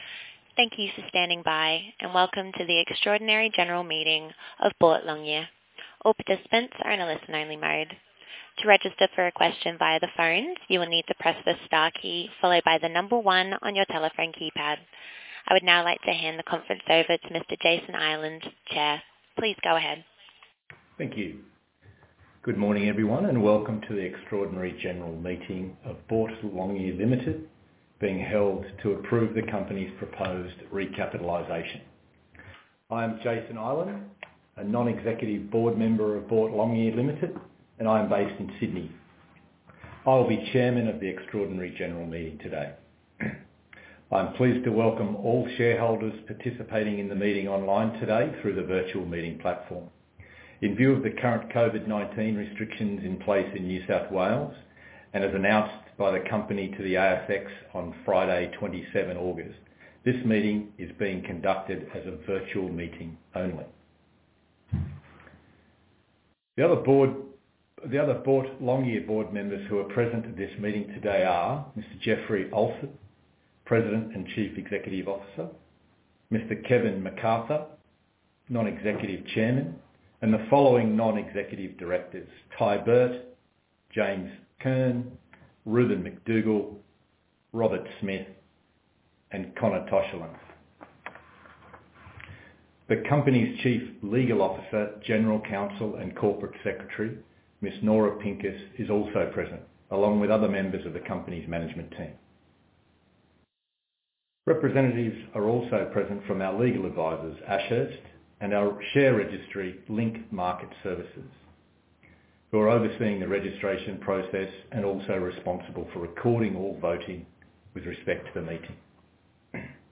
8 SEPTEMBER 2021 EXTRAORDINARY GENERAL MEETING (VIRTUAL)